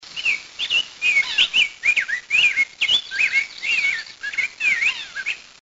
cardinal-poitrine_rose.mp3